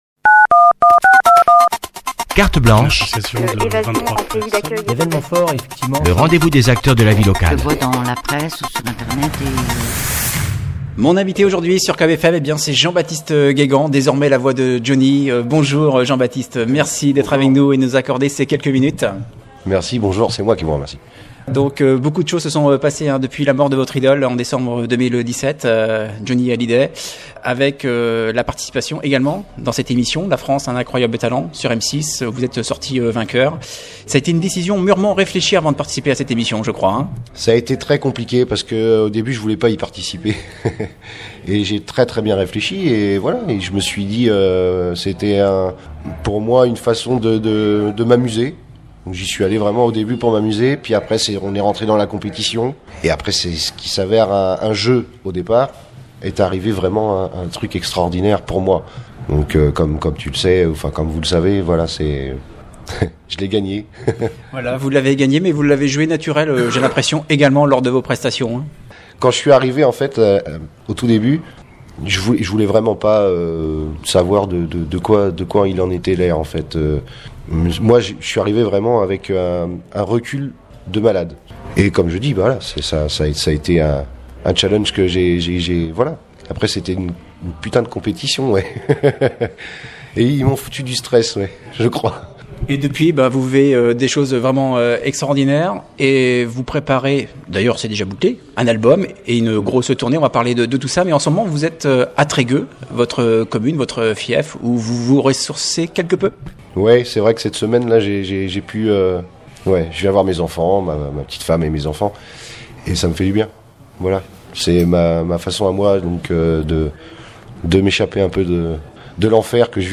j b guégan 2019L’artiste costarmoricain nous a accordé un entretien pour nous parler de son actualité très chargée depuis notamment sa victoire dans l’émission ‘ La France a un incroyable talent’, à savoir la préparation d’un album en hommage à son idole et une énorme tournée à partir du mois de mai …